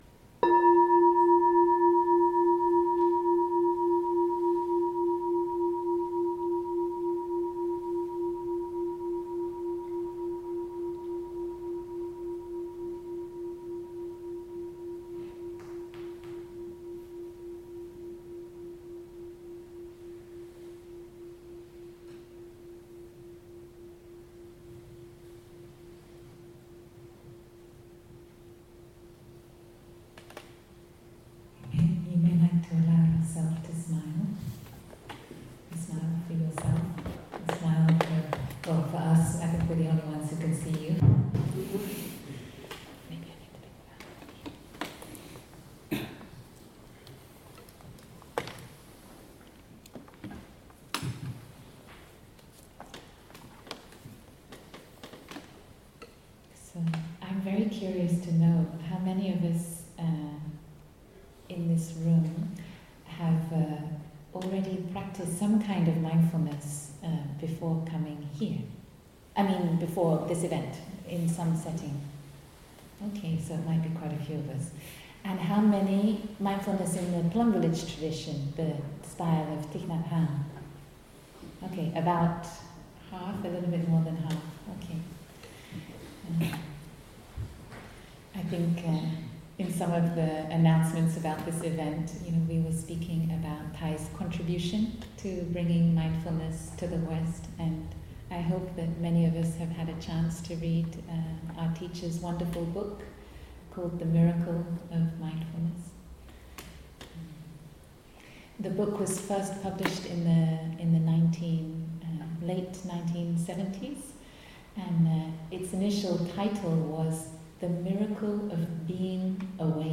Dharma talk from last nights